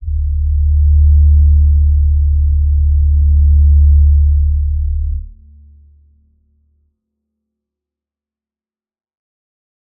G_Crystal-D2-f.wav